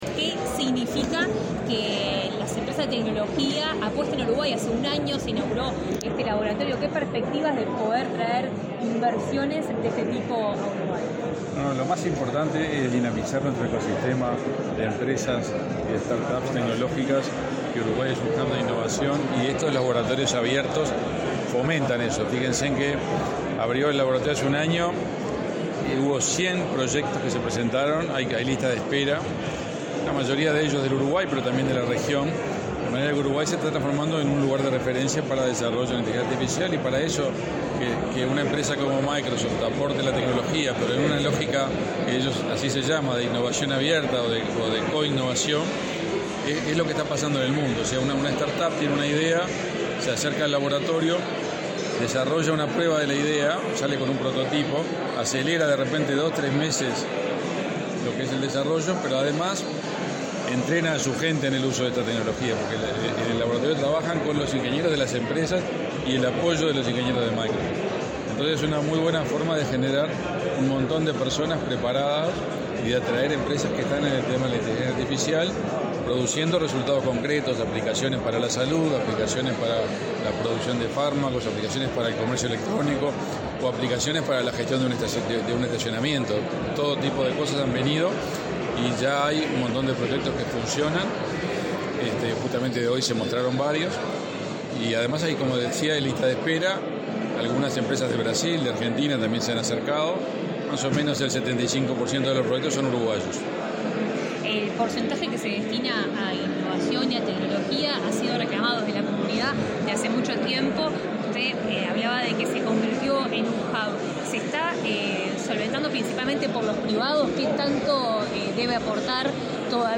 Declaraciones a la prensa del canciller Omar Paganini